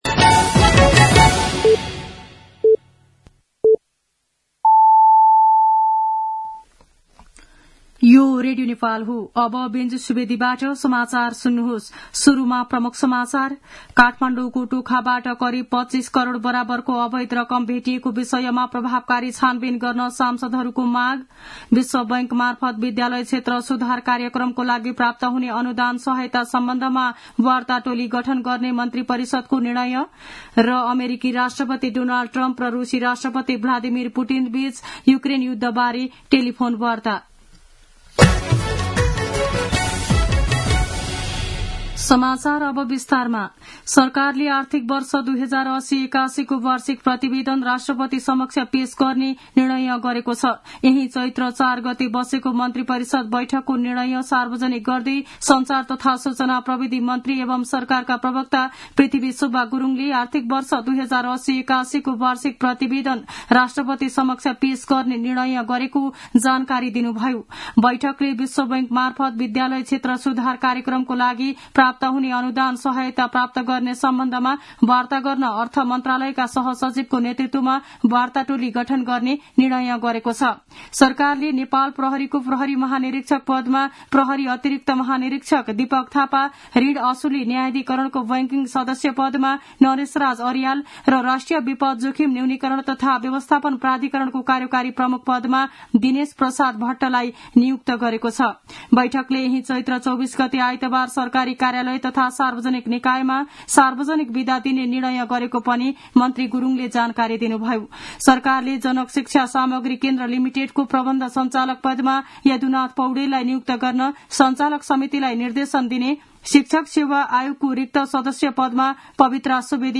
दिउँसो ३ बजेको नेपाली समाचार : ६ चैत , २०८१
3-pm-News-12-06.mp3